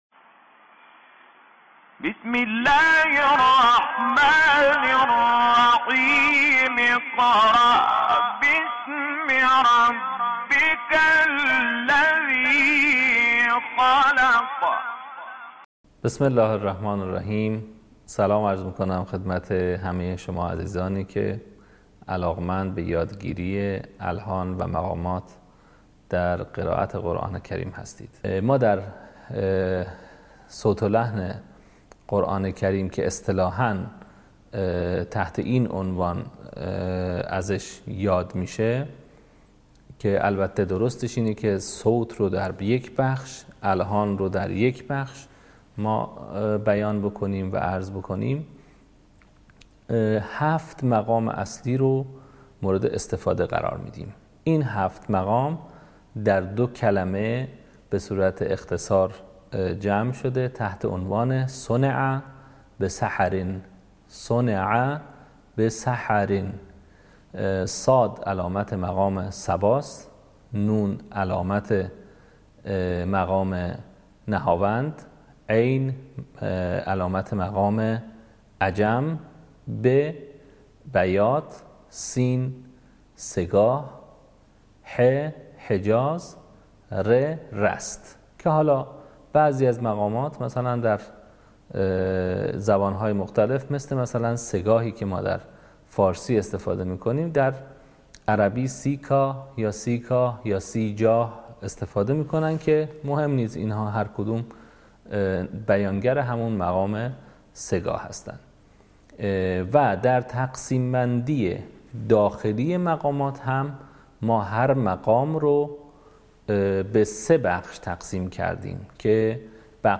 به همین منظور مجموعه آموزشی شنیداری(صوتی) قرآنی را گردآوری و برای علاقه‌مندان بازنشر می‌کند.
آموزش قرآن